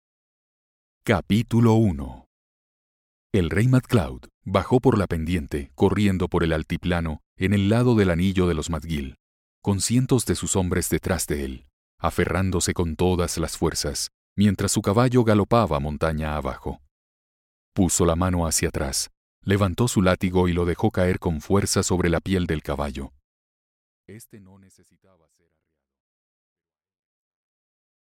Аудиокнига El Destino De Los Dragones | Библиотека аудиокниг